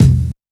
• Kick Sample E Key 197.wav
Royality free kick drum sample tuned to the E note. Loudest frequency: 293Hz
kick-sample-e-key-197-T41.wav